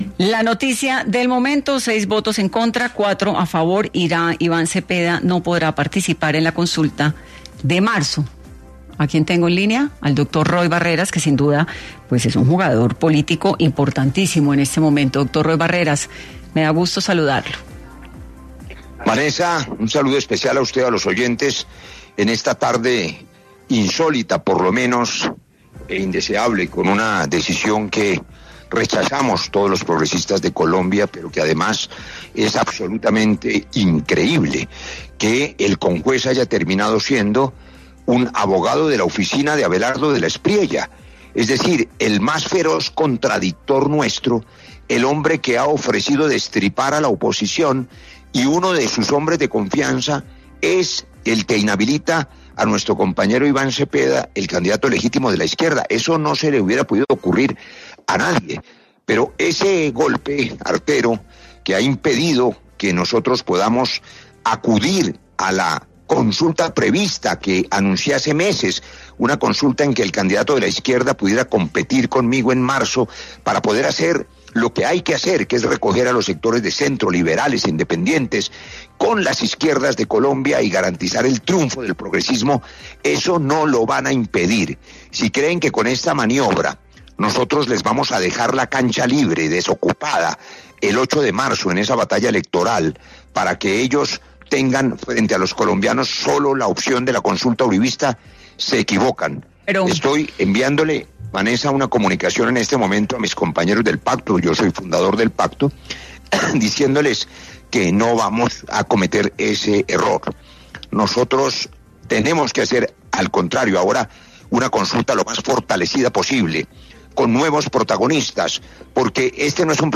Al respecto, habló en los micrófonos de Dos Puntos, de Caracol Radio con Vanessa de la Torre, el precandidato Roy Barreras, que sí participará en la consulta ya mencionada.